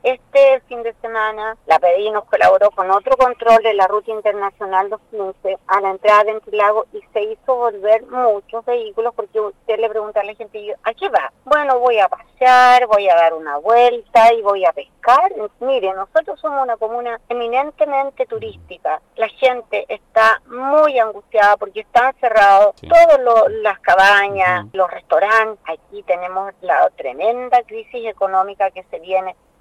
En conversación con el programa Primera Hora de Radio Sago, la alcaldesa de la comuna lacustre, María Jimena Núñez manifestó ésta y otras preocupaciones, asegurando que la población piensa que el sector es para ir de vacaciones.